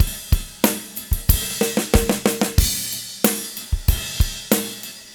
15 rhdrm93snare.wav